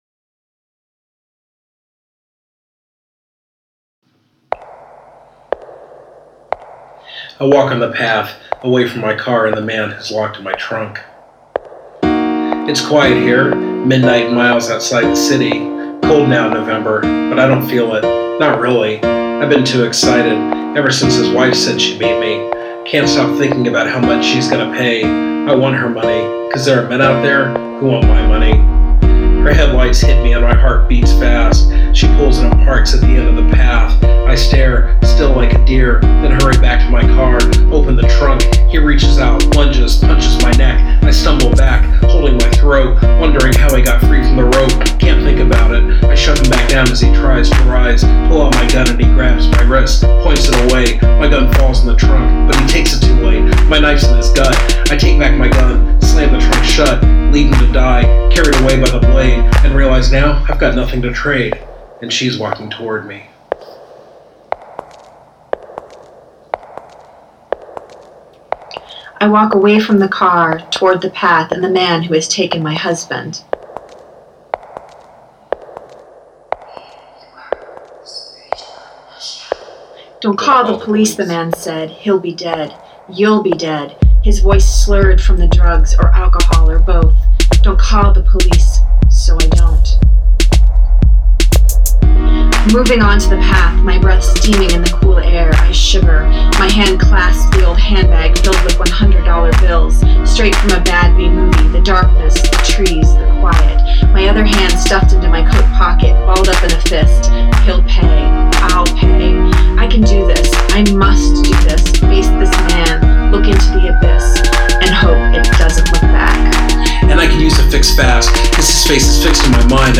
broken-w-vox.mp3